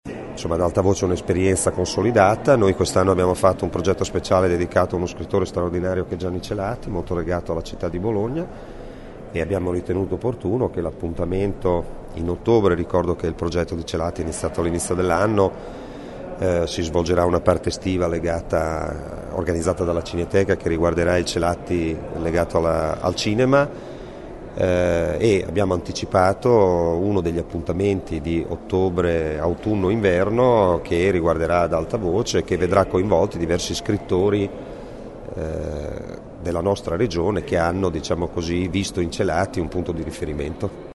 L’assessore alla Cultura del Comune di Bologna spiega il progetto